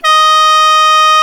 THAI PIPES05.wav